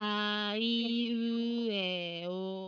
stream 1/1 - extracted left-hand voice stream 1/2 - extracted right-hand voice mixture 2 - two voices plus synthetic tone stream 2/1 - extracted left-hand voice stream 2/2 - extracted right-hand voice stream 2/3 - extracted tones (center)